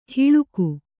♪ haḷaga